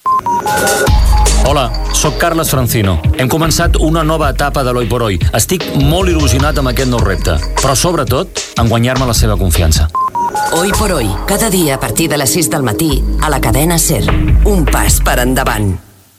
Promoció del programa a Catalunya
Info-entreteniment